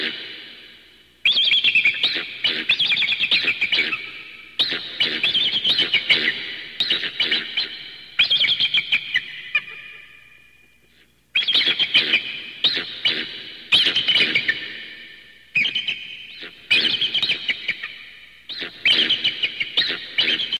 Category: Bird Ringtones